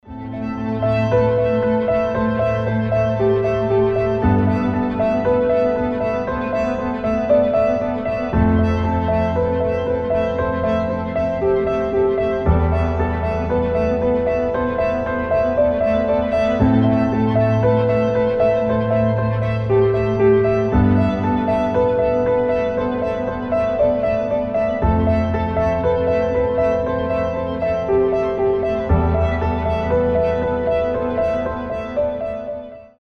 • Качество: 320, Stereo
без слов
красивая мелодия
Neoclassical